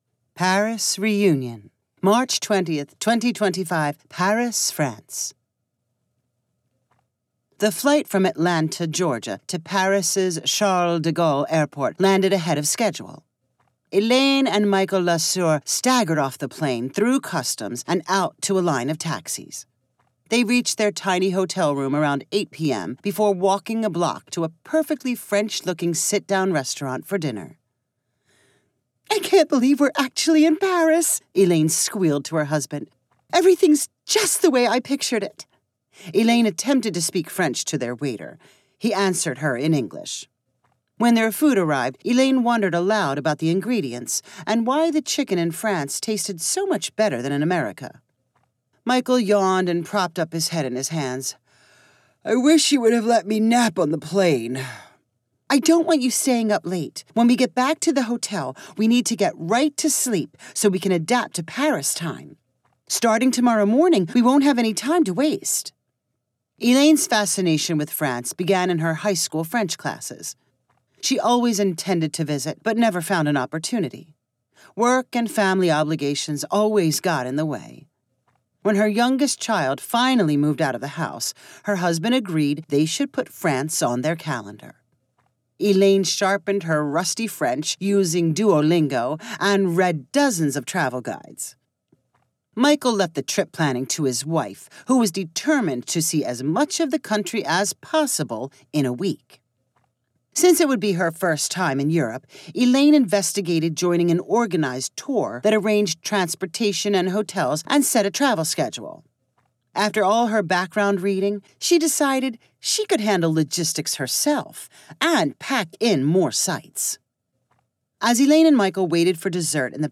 The stories are energetically narrated, ensuring youll smile and laugh out loud.